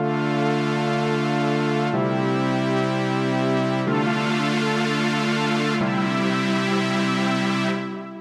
Atmospheric Chords
Rather than keeping your techno chords ultra clean, you can exploit the many atmosphere-adding parameters of JC-120 to strong effect.
Here the microphone has been placed just off center with a long distance with the Level knob cranked right up.
JC-120-Pad.wav